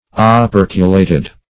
Operculate \O*per"cu*late\, Operculated \O*per"cu*la`ted\, a.